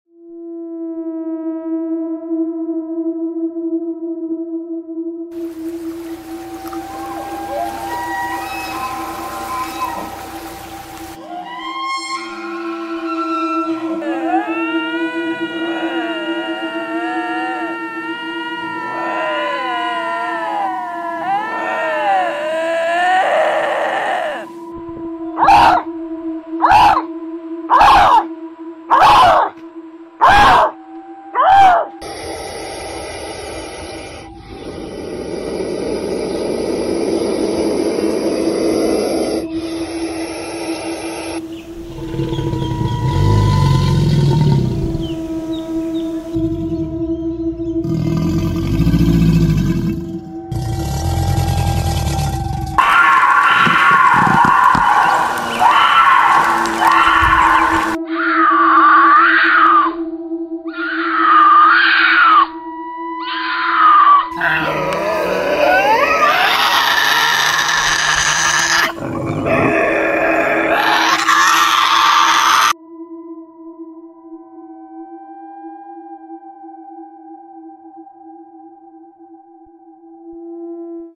The scariest animal sounds of sound effects free download